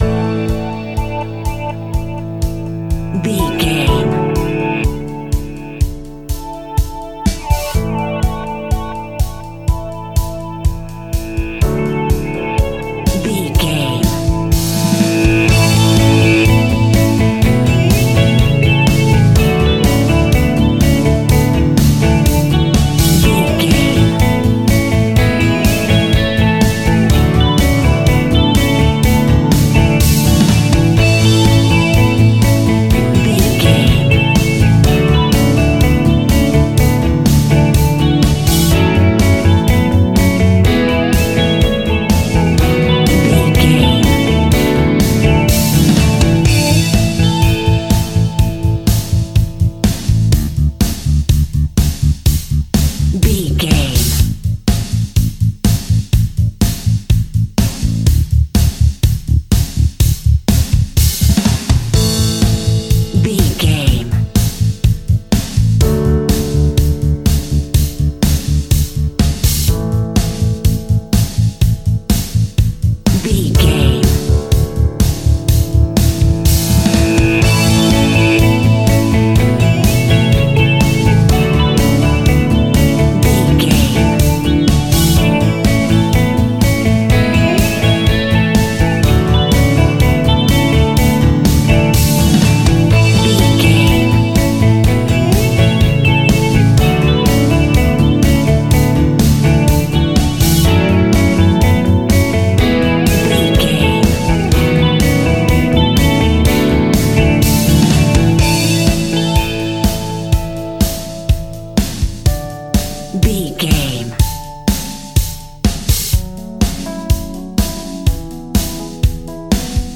Uplifting
Ionian/Major
pop rock
fun
energetic
instrumentals
indie pop rock music
guitars
bass
drums
piano
organ